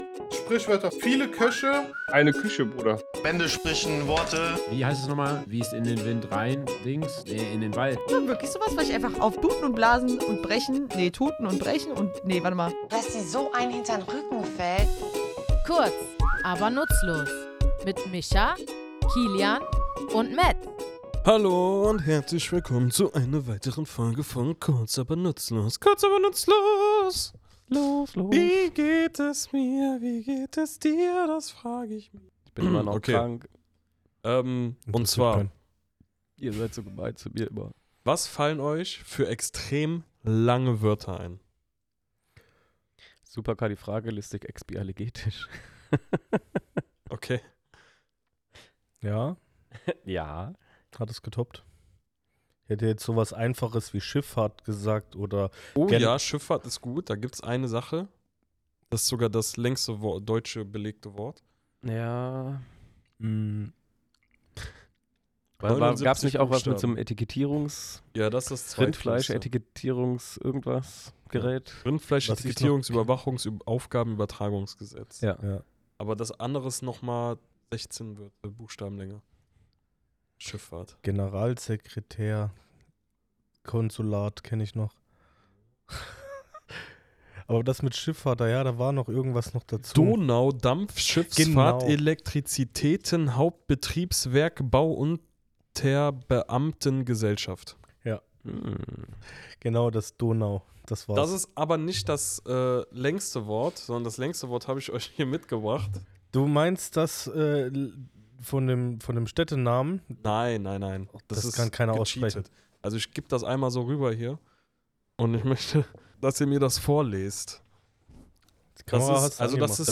Was macht sie so schwierig, warum bringen sie uns ins Stolpern, und wieso faszinieren sie Sprachliebhaber schon seit Jahrhunderten? Wir, drei tätowierende Sprachnerds, nehmen euch in unserem Tattoostudio mit auf eine Reise durch die Geschichte und Bedeutung dieser Sprachspiele.